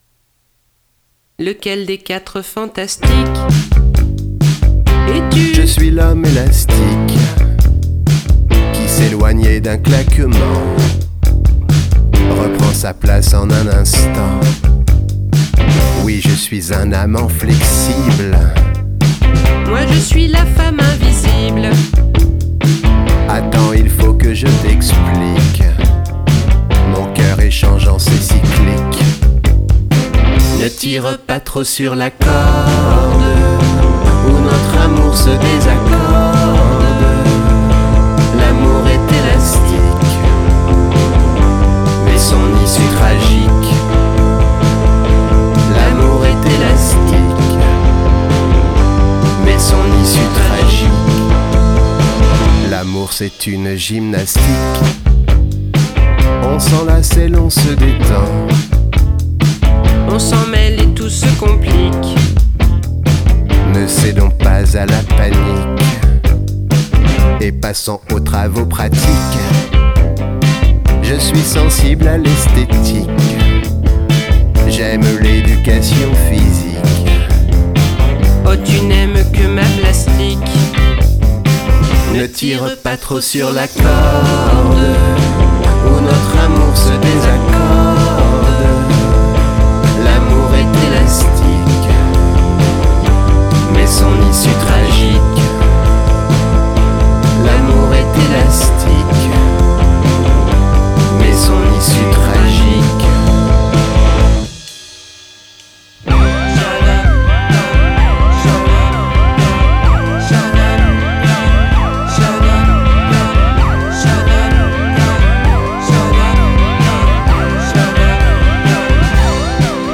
rock rétro ébouriffant et impertinent